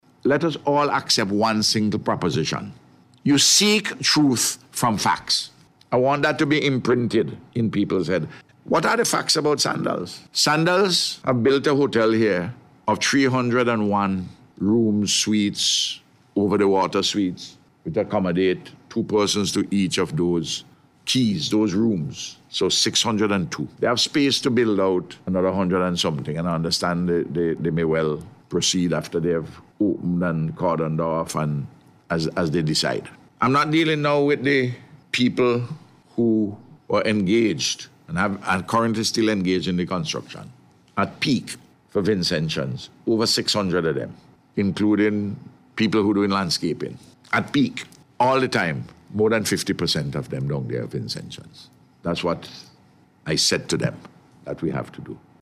Dr. Gonsalves made the statement during a news conference at Cabinet Room yesterday.